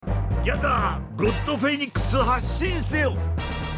プロモーションビデオなので、景気のよいBGMが流れていますが、最初の「ギャザー」が掠れた声になっているのが残念です。